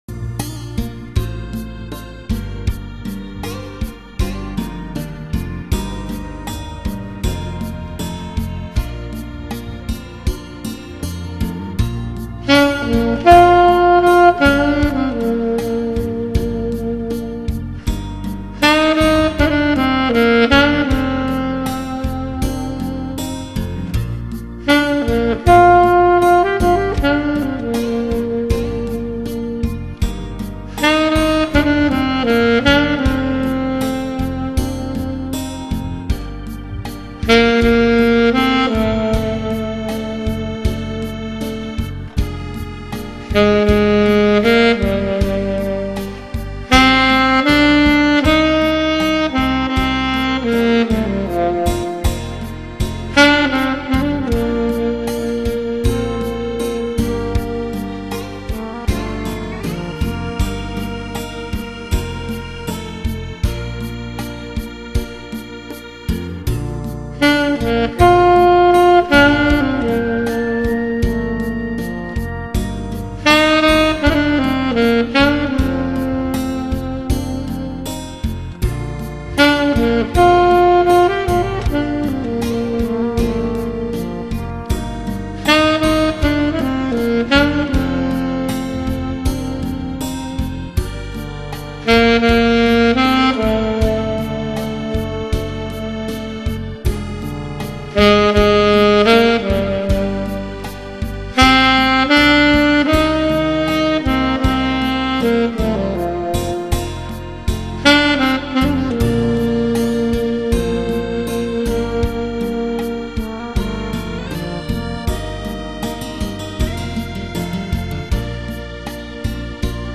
이번에는 앨토색소폰으로 연주해봤습니다...